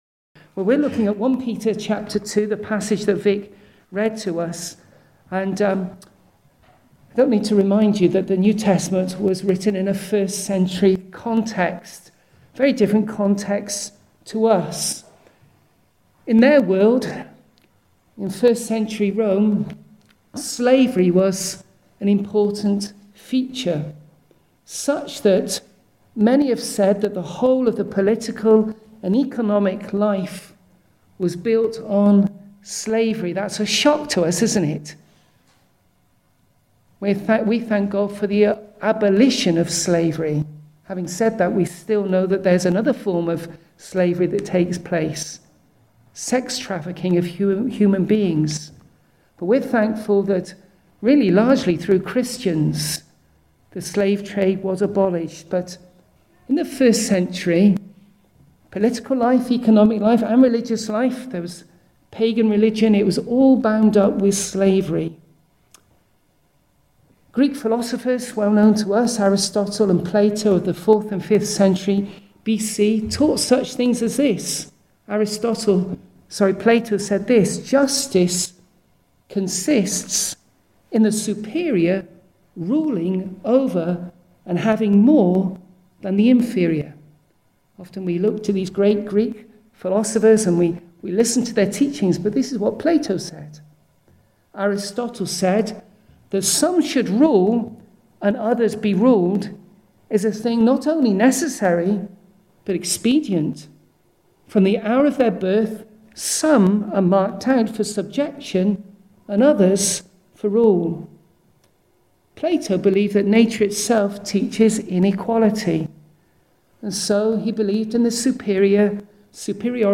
Passage: 1 Peter 2:7-25 Service Type: Sunday Morning